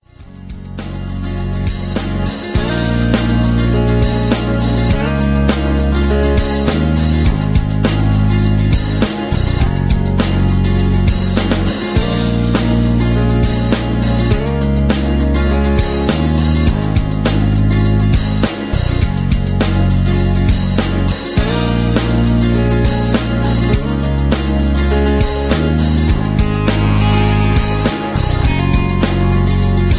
Tags: karaoke , sound tracks , rock